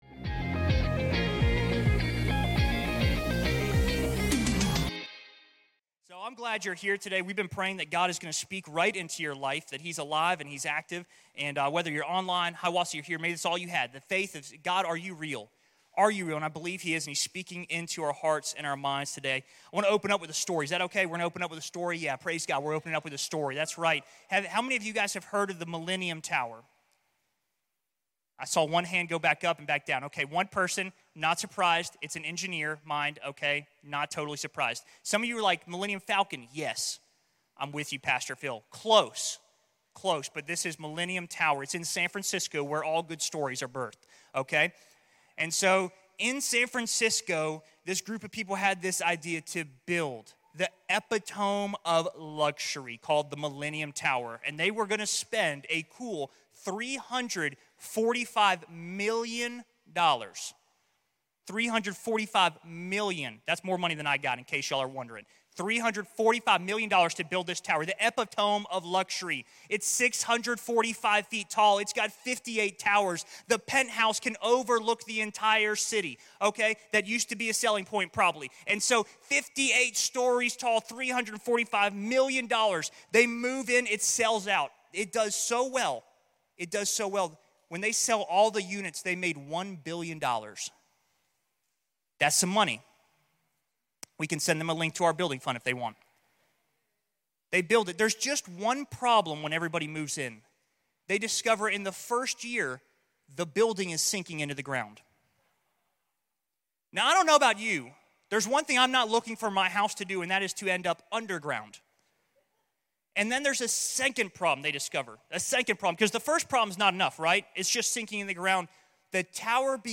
Make It Count | Vertical Church of the Mountains